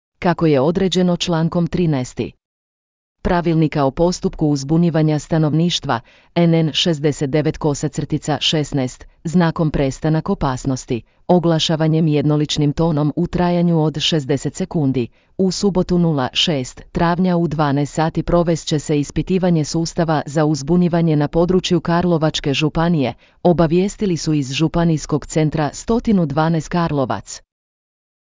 Ispitivanje sustava za uzbunjivanje na području Karlovačke županije
Kako je određeno člankom 13. Pravilnika o postupku uzbunjivanja stanovništva  (NN 69/16), znakom prestanak opasnosti (oglašavanjem jednoličnim tonom u trajanju od 60 sekundi) u subotu 06. travnja u 12 sati provest će se ispitivanje sustava za uzbunjivanje na području Karlovačke županije, obavijestili su iz Županijskog centra 112 Karlovac.